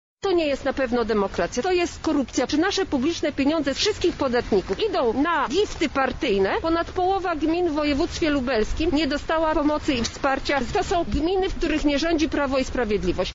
— mówi Marta Wcisło, posłanka KO.